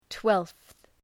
Προφορά
{twelfɵ}